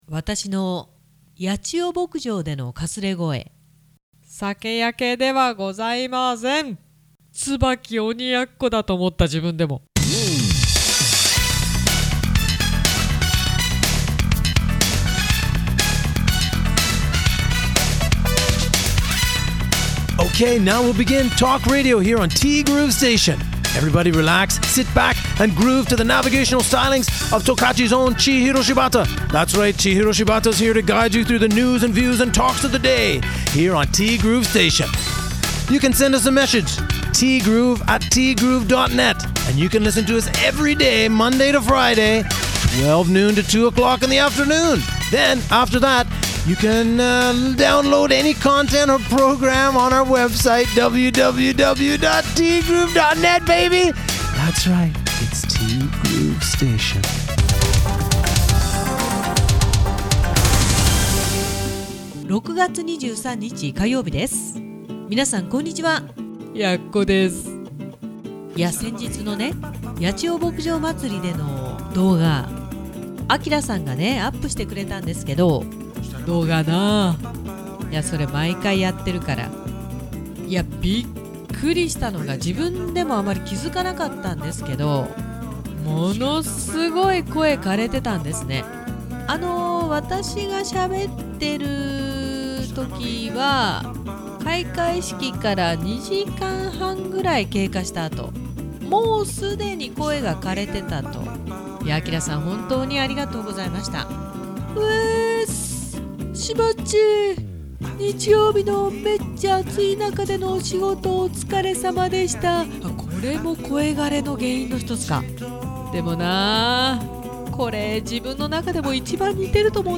T GROOVE STATION » Blog Archive » ６月２３日（火）「声が・・・声が・・・枯れてる～～～」